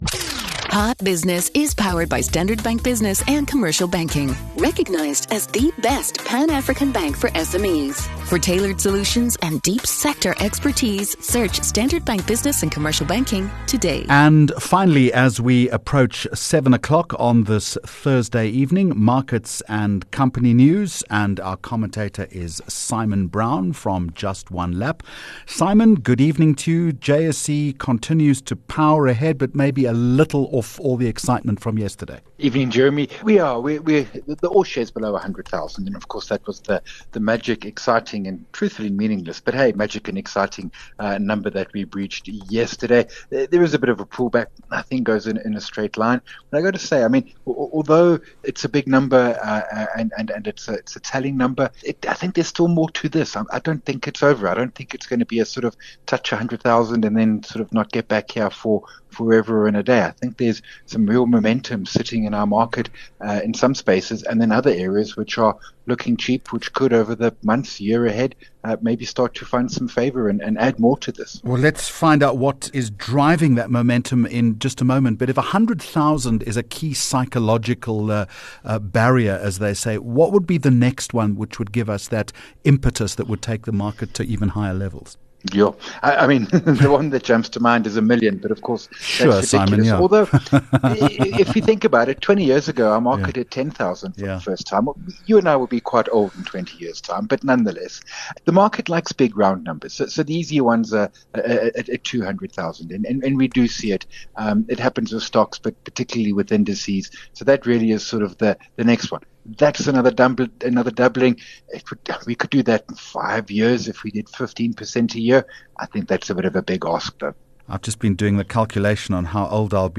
Markets Guest